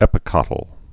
(ĕpĭ-kŏtl)